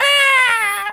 monkey_hurt_scream_06.wav